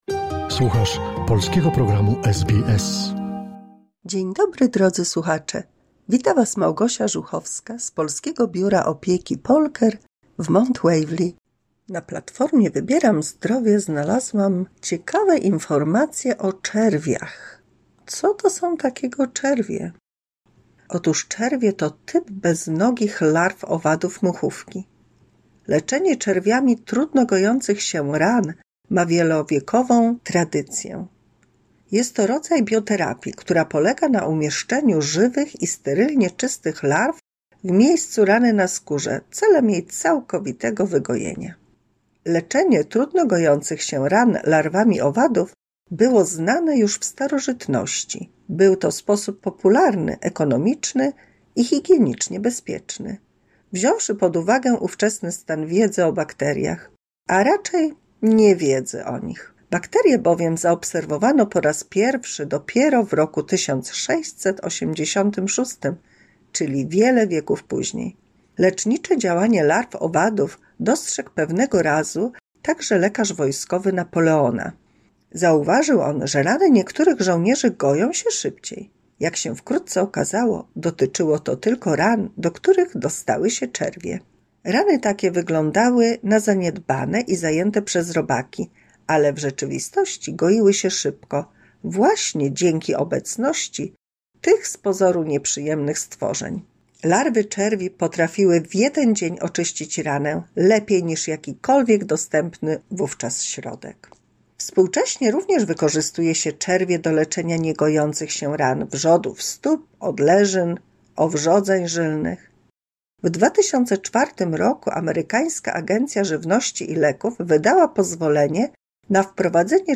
W 211 mini słuchowisku dla polskich seniorów dowiemy się o właściwościach czerwi, czyli robaków leczących rany oraz o Jadwidze, która została świętą...